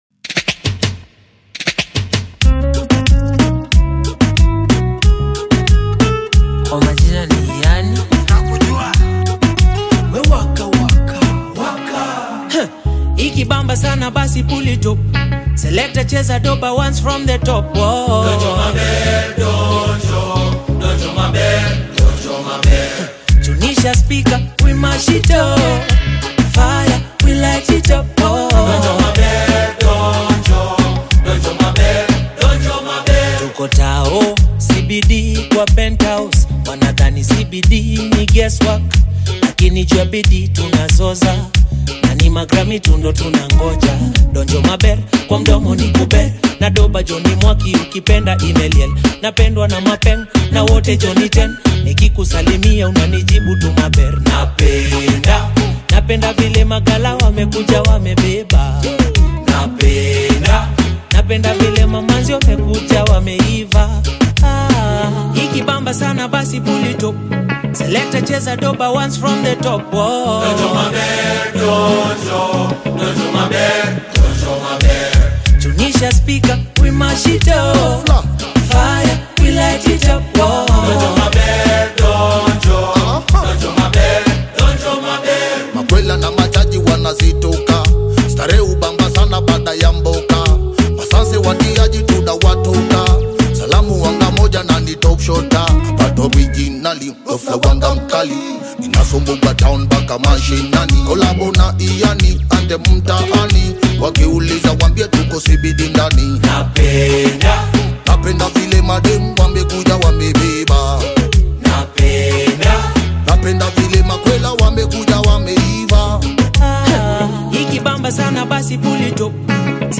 Afrobeat
a pulsating track built to get you on your feet.
Infused with infectious beats and rhythmic grooves
seamlessly blended with a fresh Afro Dancehall vibe.
• Genre: Afrobeat